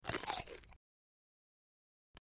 Juicy, Crunchy Bites Into Vegetable, X5